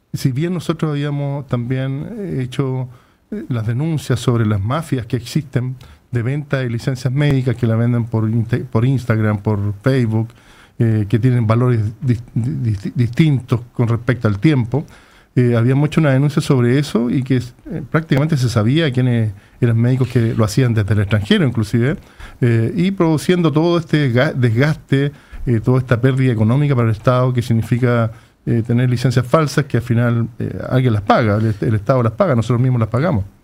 En conversación con Radio Paulina